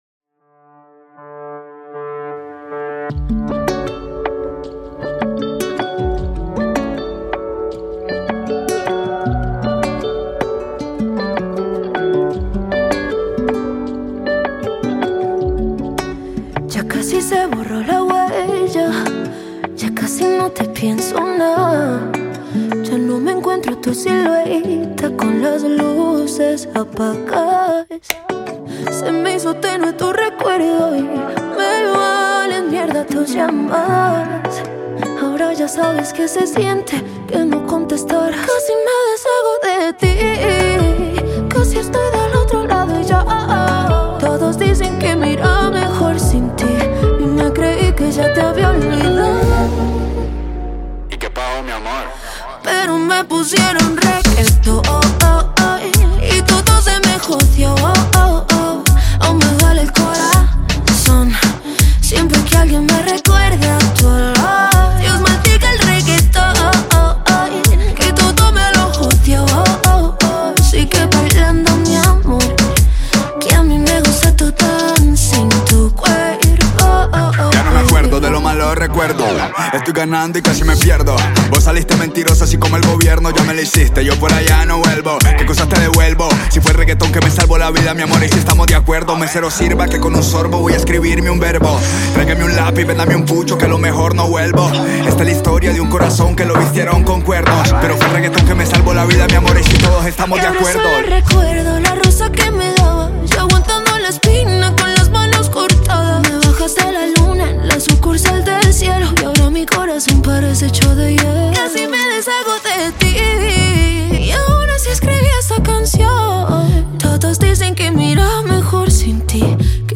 artista y compositor Colombiano de música urbana.